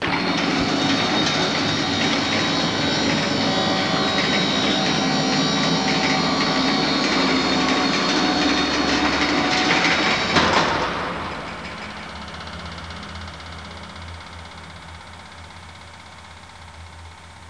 1 channel
garagtor.mp3